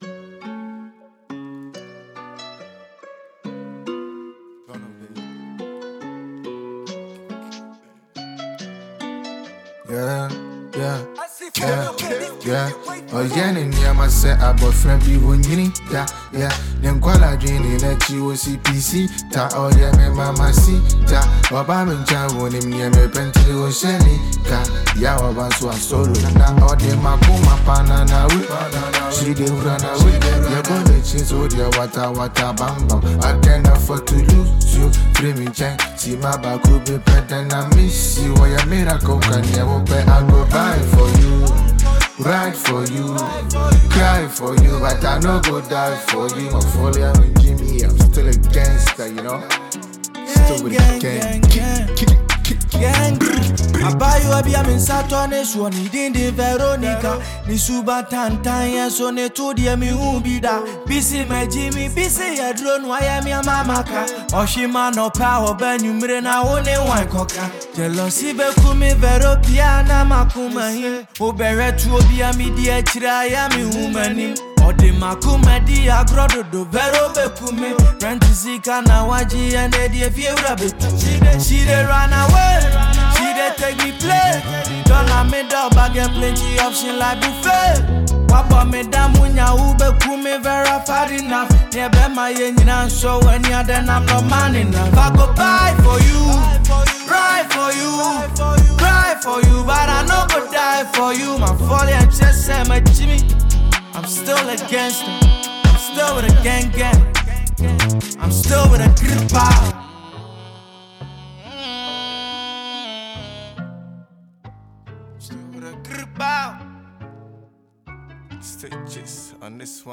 a Ghanaian trapper
Gh rapper